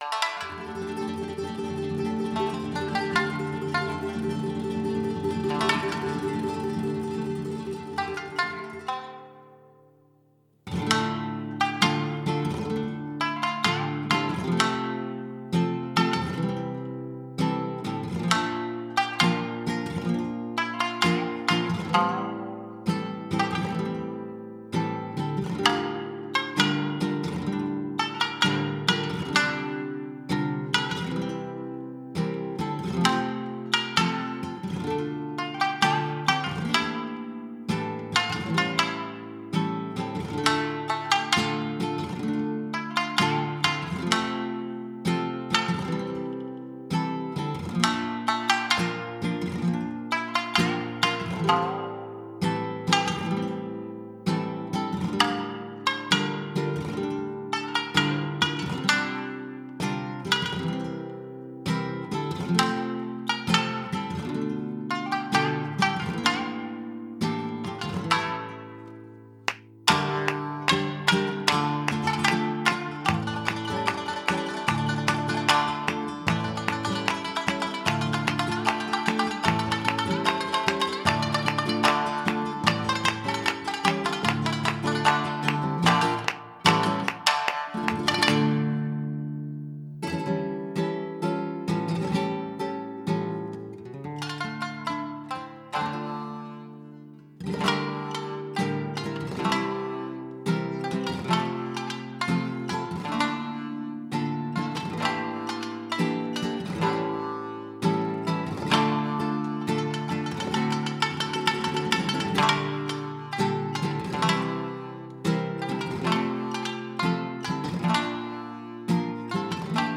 farruca4.mp3